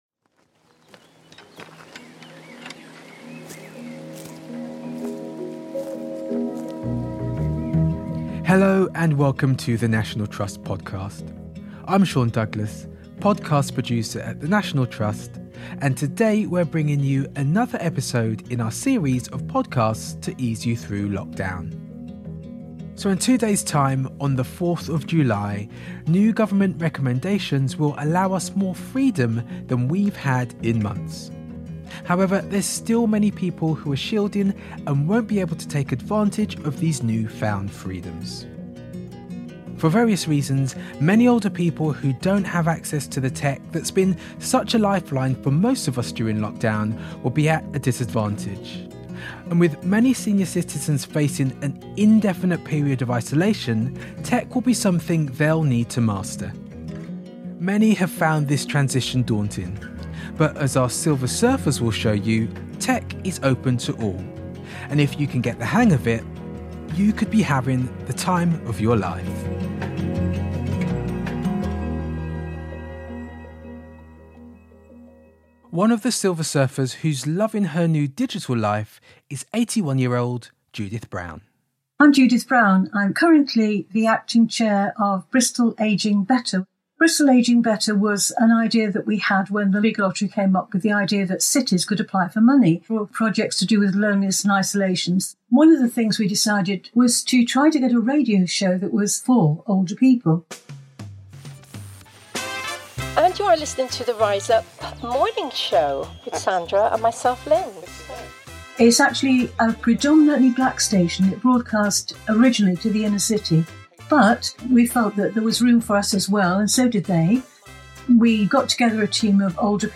In this episode, over-60s share how their lives have been transformed by tech, from keeping in touch with loved ones to adventuring to far-flung parts of the world.